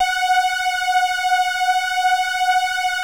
PULZEFLANG.9.wav